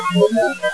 This friendly spirit says.....